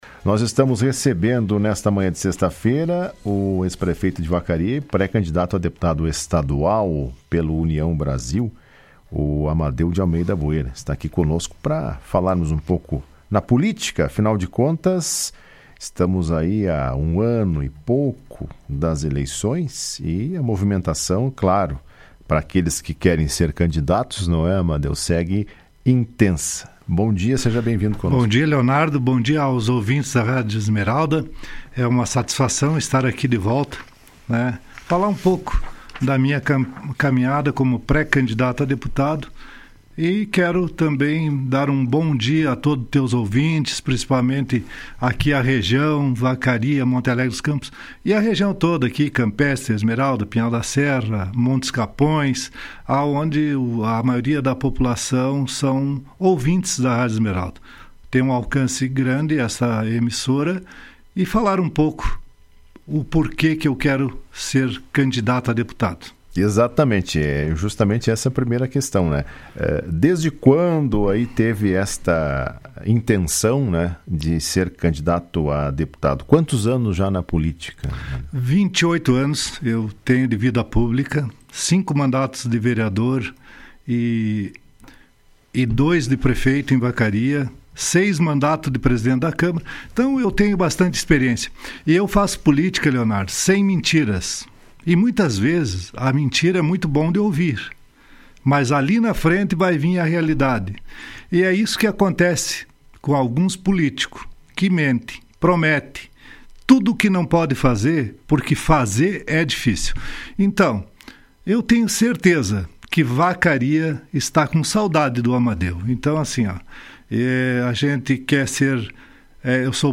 O ex-prefeito de Vacaria e pré-candidato a deputado estadual, Amadeu de Almeida Boeira, participou do programa Comando Geral da última sexta-feira. Durante a entrevista, relatou os motivos pelos quais deseja ser deputado. Ele afirmou que está visitando diversos municípios e buscando apoio de lideranças representativas e empresários, que vão auxiliar num futuro trabalho na assembleia legislativa.